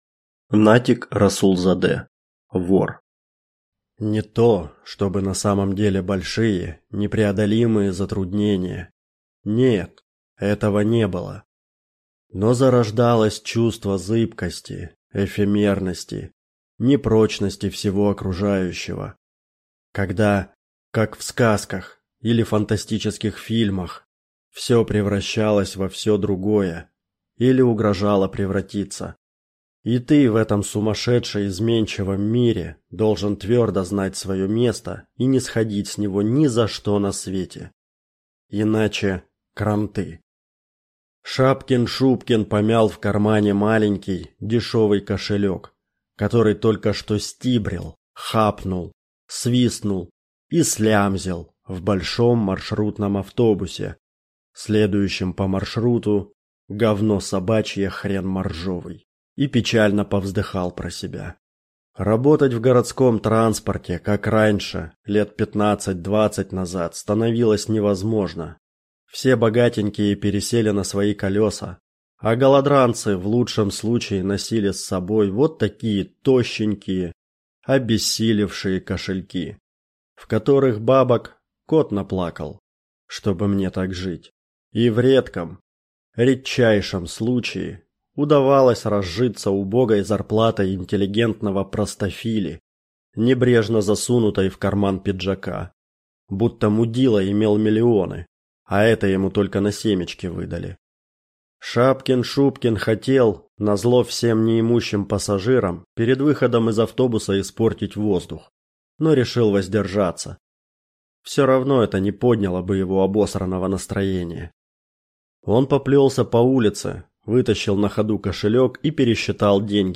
Аудиокнига Вор | Библиотека аудиокниг